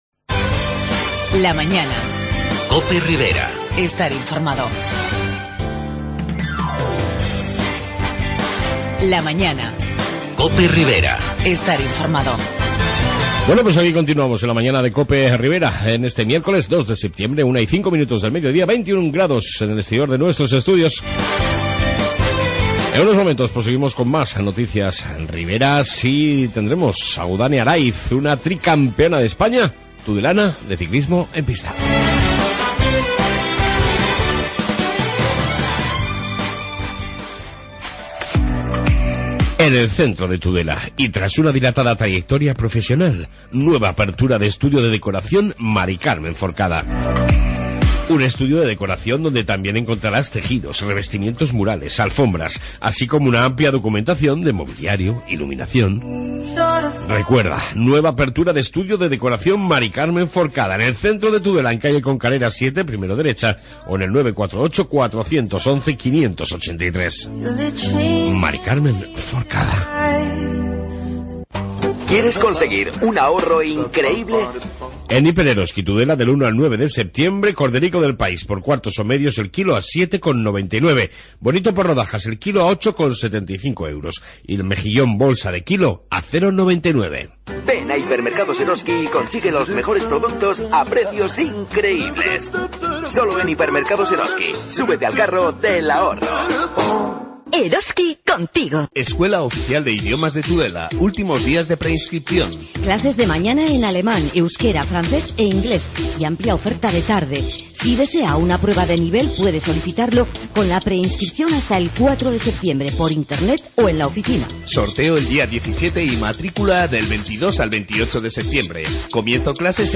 AUDIO: En esta 2 parte Noticias Riberas y entrevista